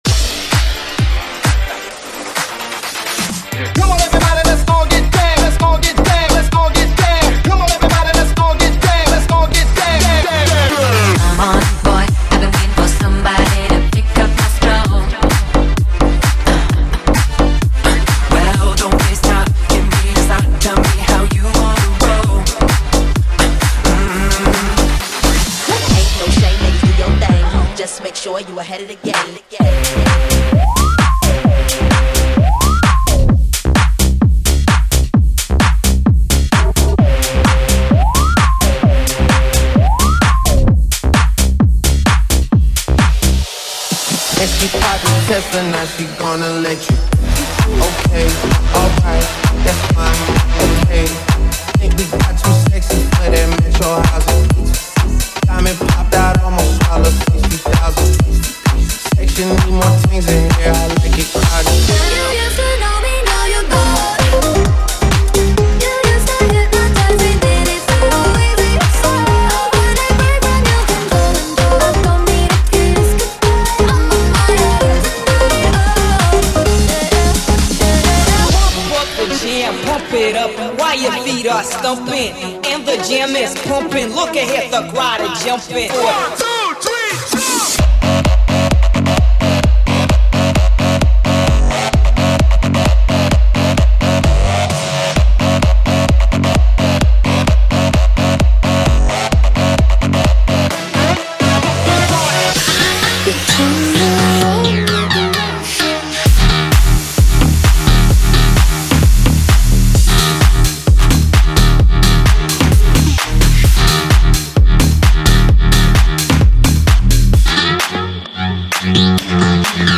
BPM: 130|140|150 (56:00)
Format: 32COUNT
Always Radio, Club & Classics in Anthem & Harder Sounds.